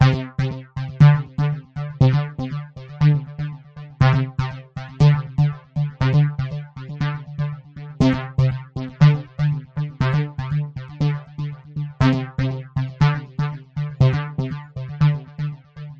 工业循环 " 克雷克尔H8线
描述：电子回路（120 bpm）
Tag: 电子 房子 介绍 恍惚间 舞蹈 工业